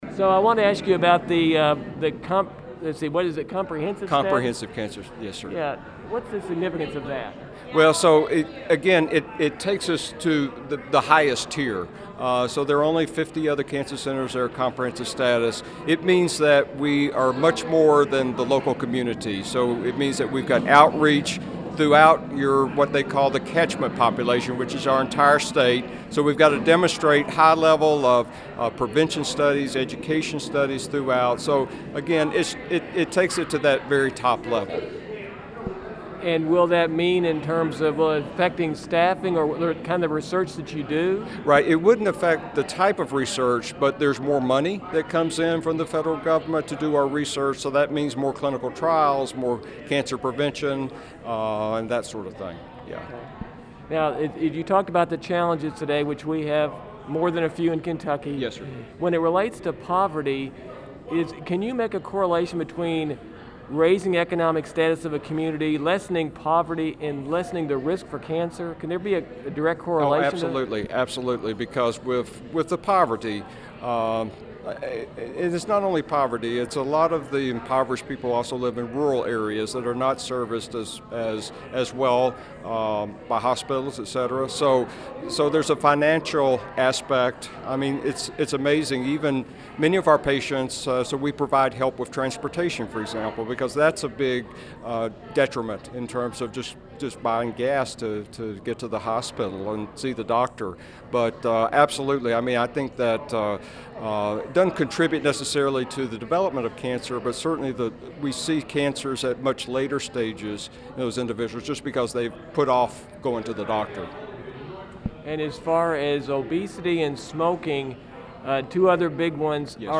Here's the entire interview